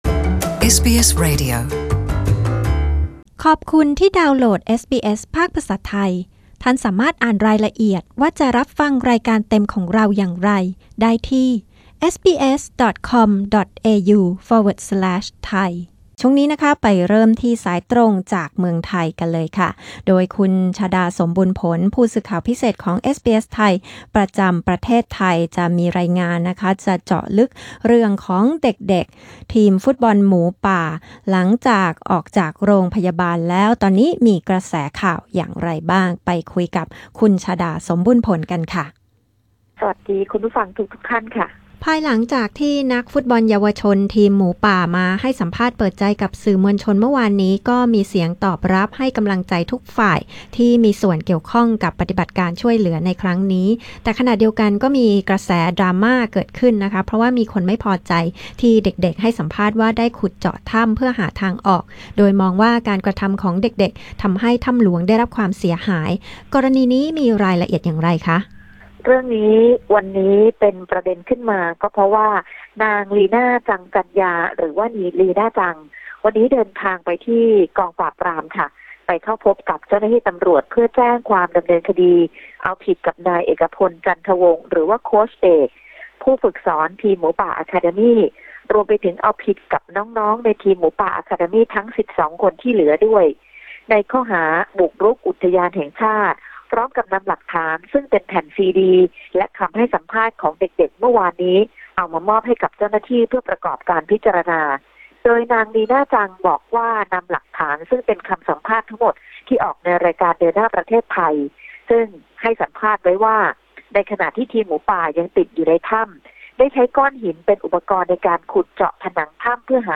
Thai phone-in news 19 JULY 2018